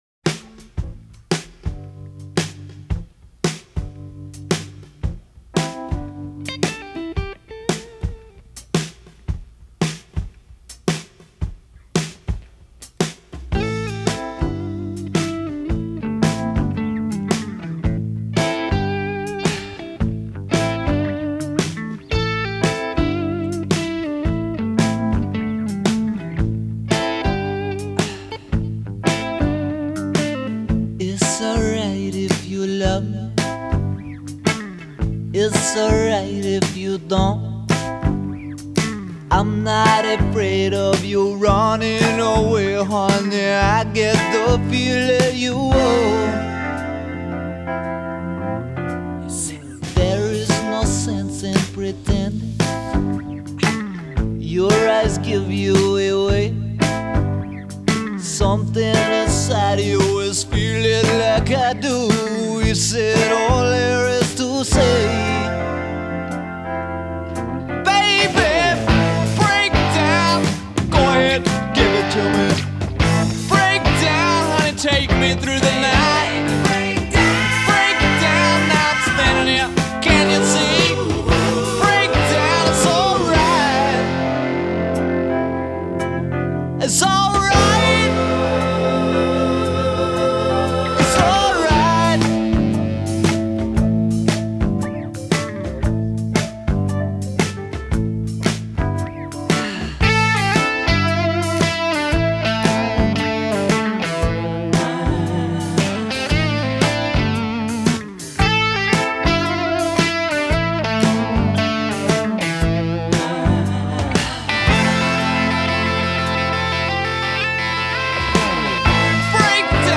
classic rock melodies